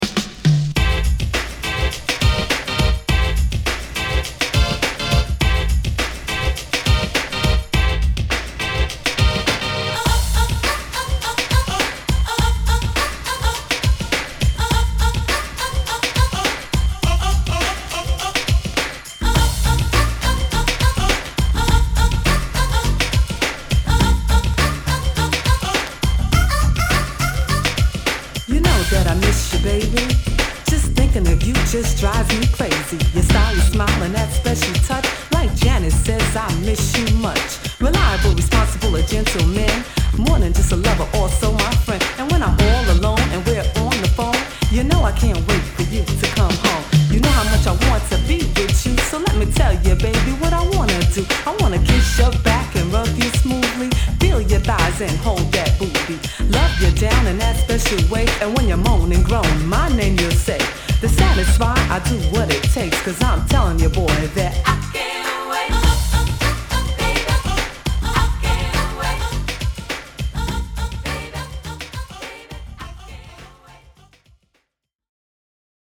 ・ 45's HIP HOP
Rap 45's!!